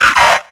Cri de Cacnea dans Pokémon X et Y.